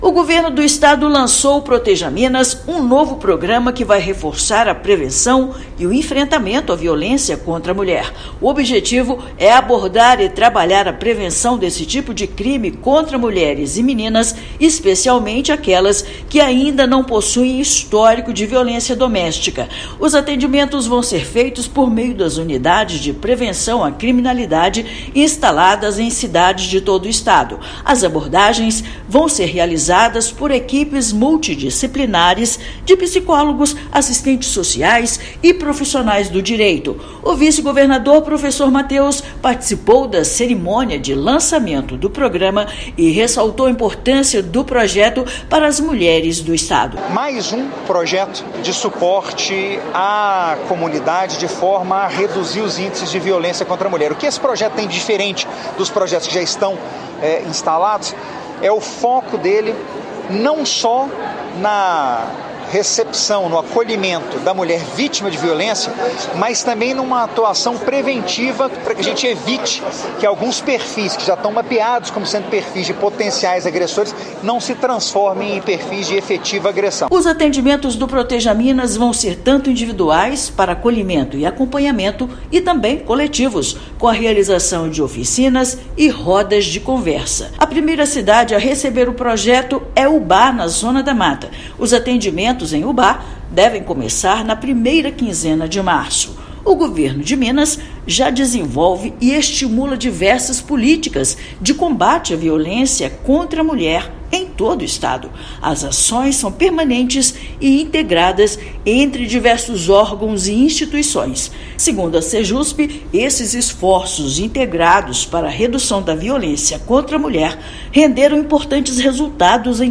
Ação é inovadora pelo trabalho realizado, também, com mulheres, adolescentes e meninas que não possuem histórico de violência. Ouça matéria de rádio.